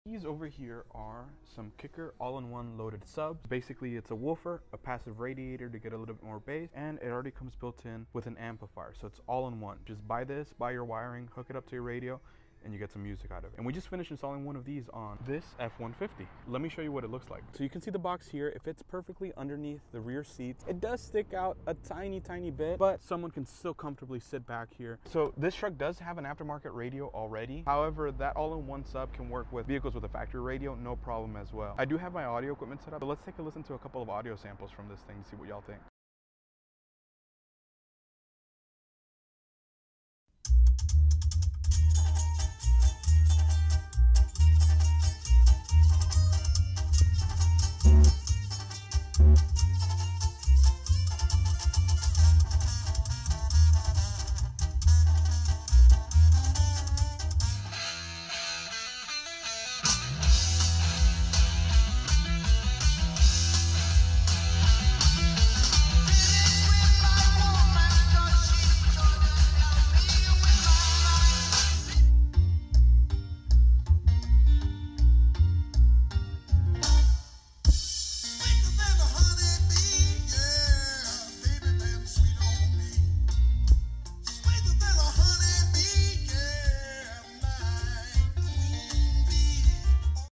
Check out how this kicker all in one enclosure sounds in this F150! This loaded box as quickly become one of our favorite choices to offer people when they are looking to add some bass to their vehicle without taking up too much space.